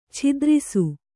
♪ chidrisu